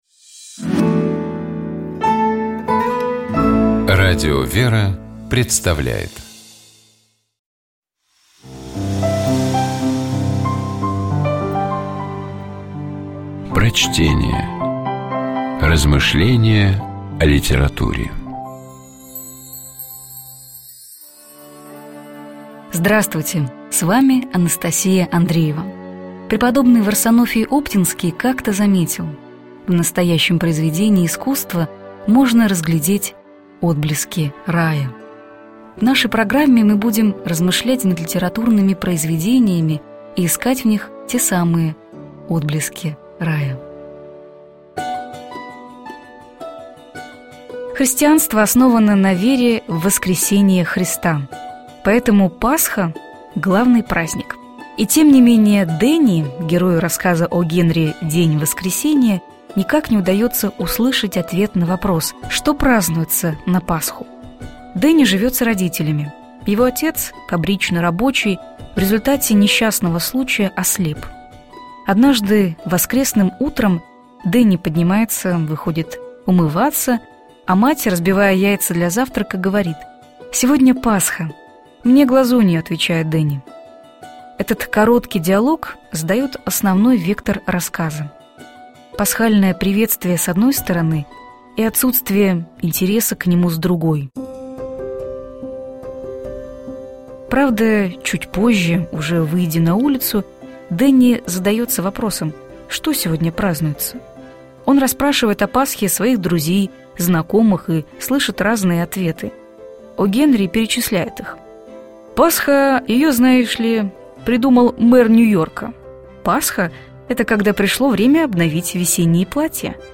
Prochtenie-O_-Genri_-Den-voskresenija-Chto-prazdnuetsja-na-Pashu.mp3